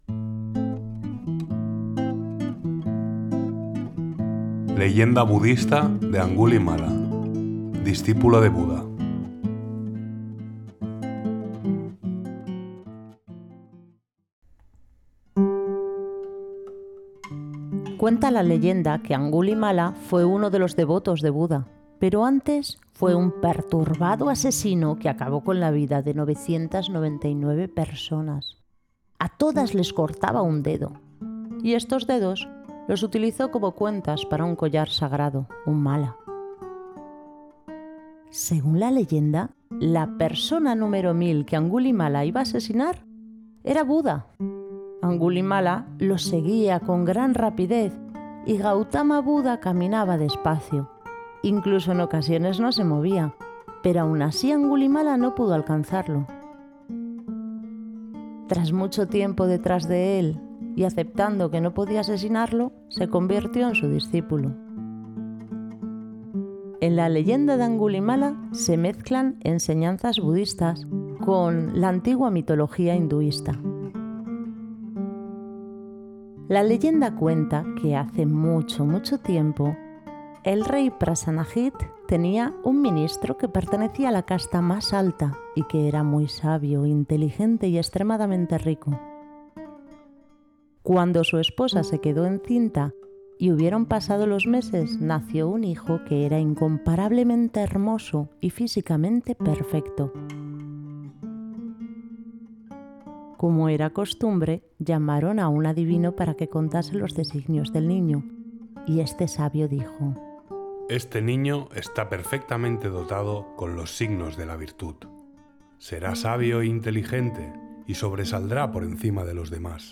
🔊 Narrado en Español-Castellano (voz humana)
🎶 Música: Raga indio improvisado en guitarra , inspirado en las tradiciones clásicas de la India,